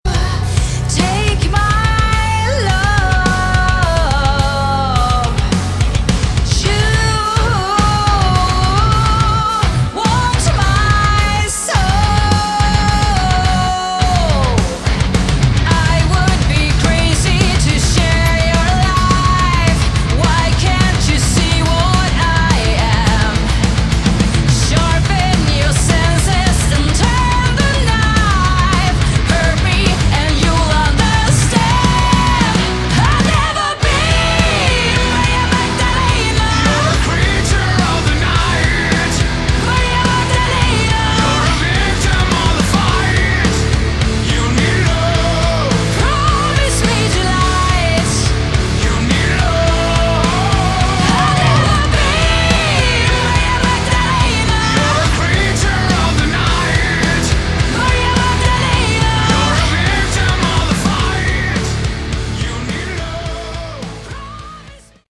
Category: Melodic Metal
guitars, bass, drums
vocals
guest keyboards